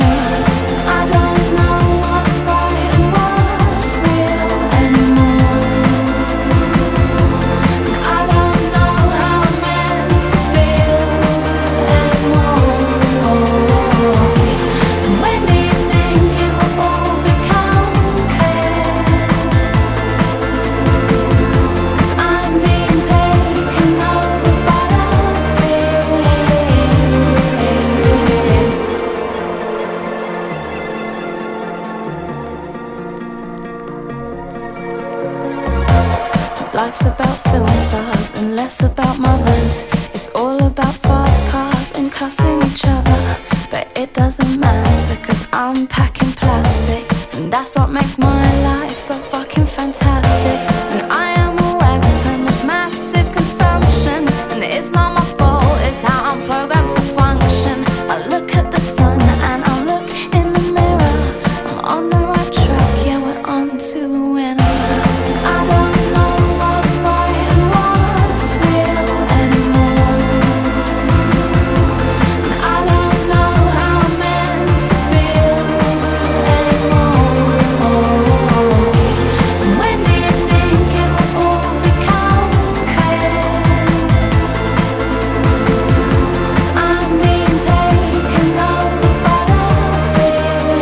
清涼感たっぷりの打ち込みインディー/ブレイクビーツ・ポップ名作！